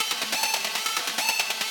Index of /musicradar/shimmer-and-sparkle-samples/140bpm
SaS_Arp04_140-A.wav